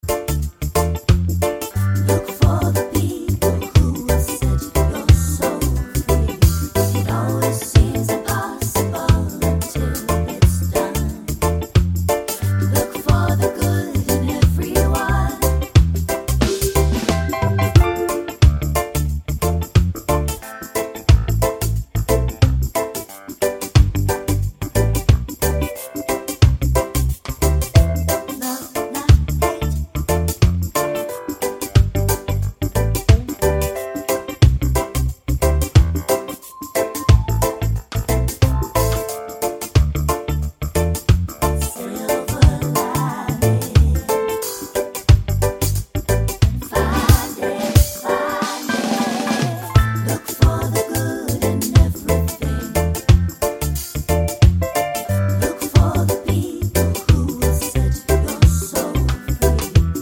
Full Length Reggae 5:10 Buy £1.50